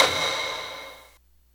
taiko-soft-hitwhistle.wav